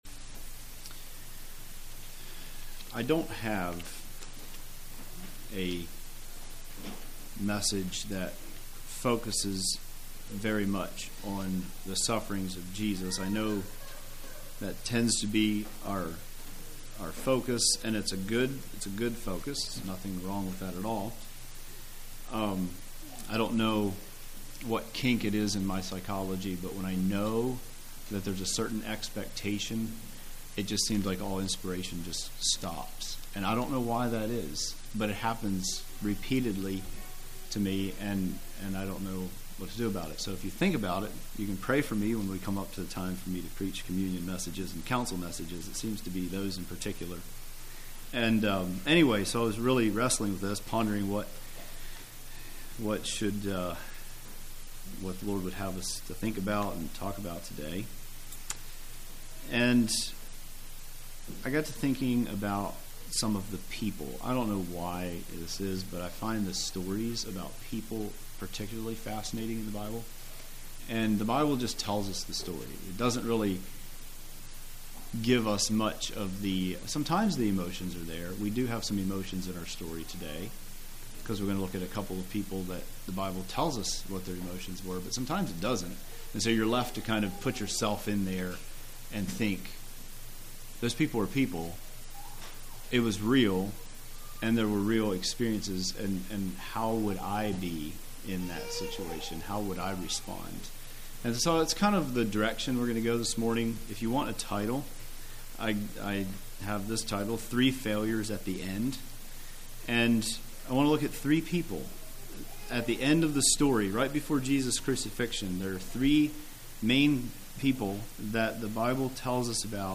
Play Now Download to Device Three Failures At The End Congregation: Pensacola Speaker